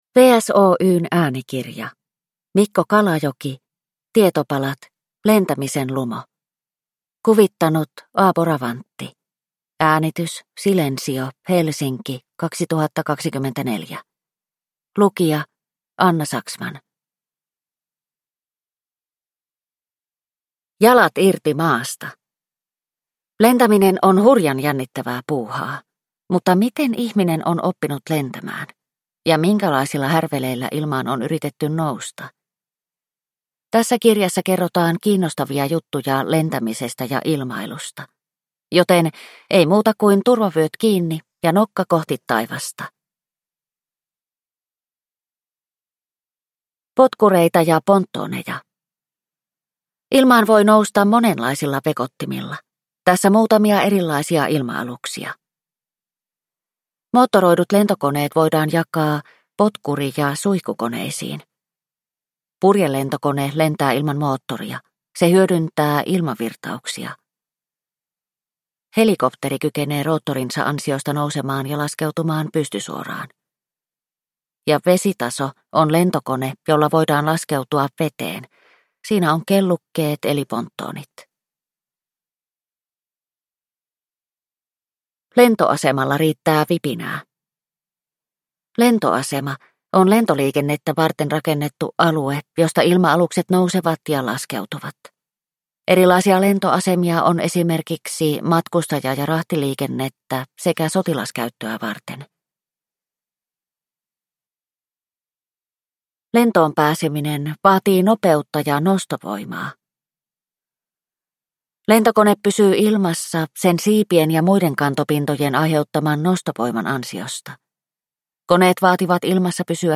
Tietopalat: Lentämisen lumo – Ljudbok